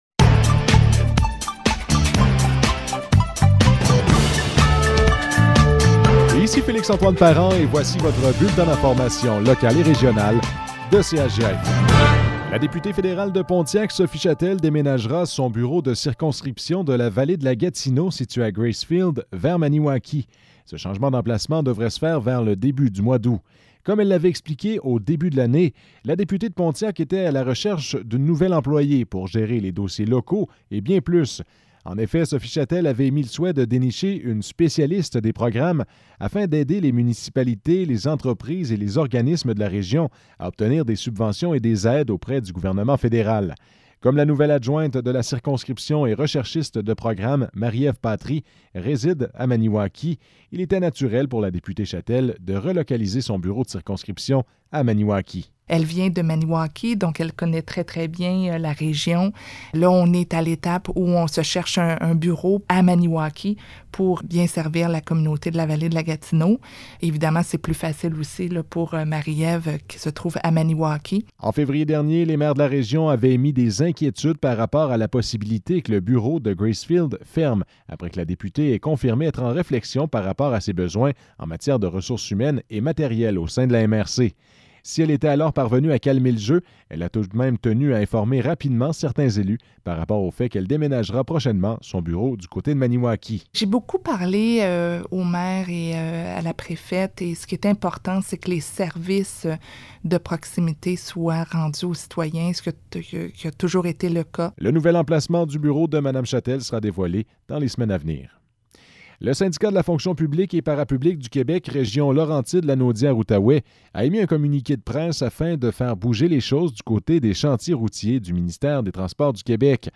Nouvelles locales - 20 juillet 2022 - 12 h